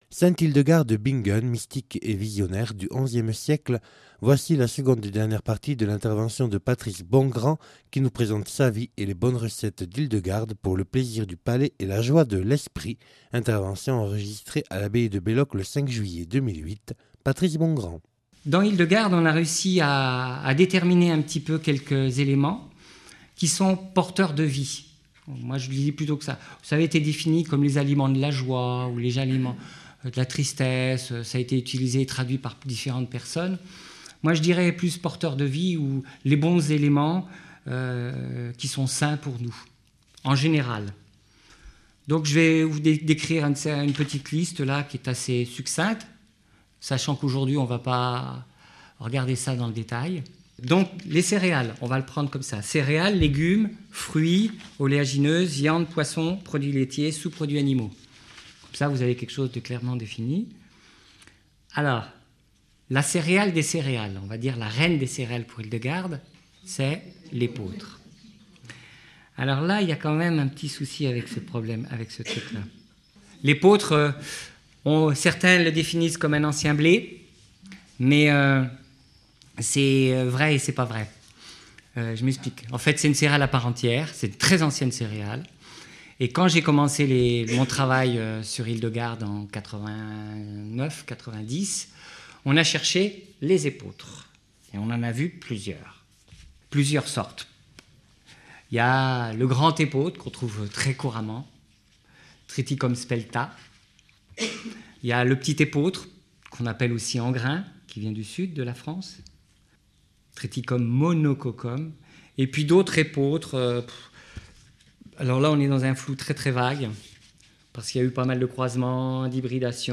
(Enregistrée le 05/07/2008 à l’abbaye de Belloc).